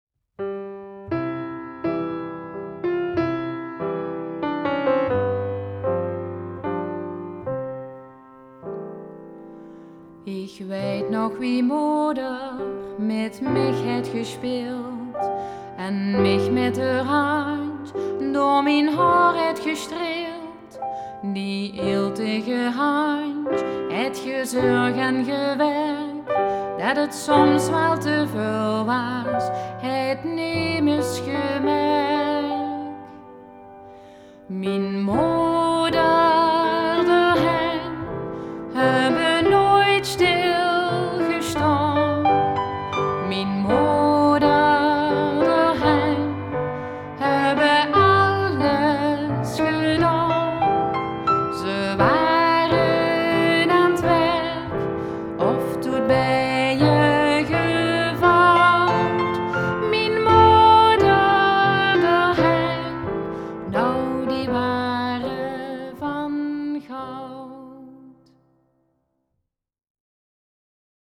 Hieronder vindt u een aantal liedjes die ik regelmatig zing.